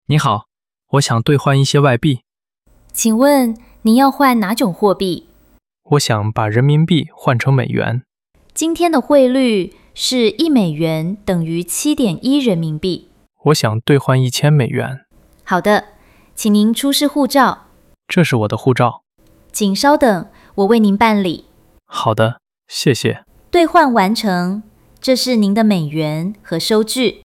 Hội thoại 3: Tại phòng dịch vụ khách hàng – Đổi ngoại tệ